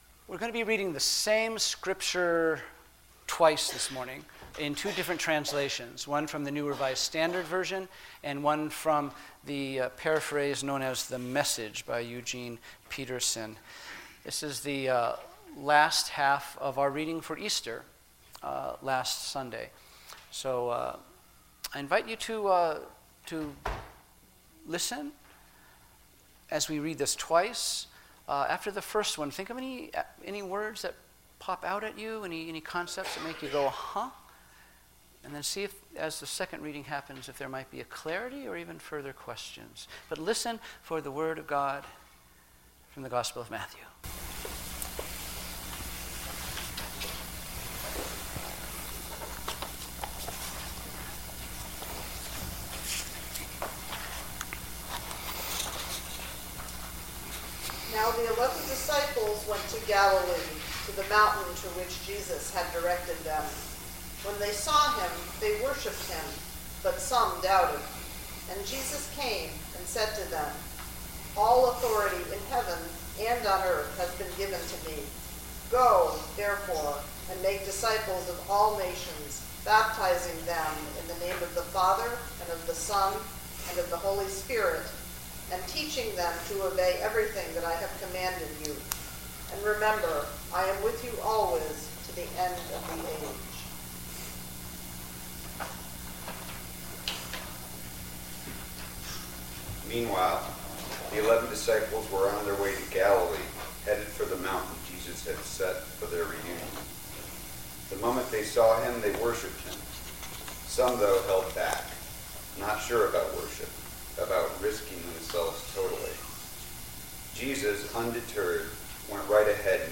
Sermons But Some Doubted.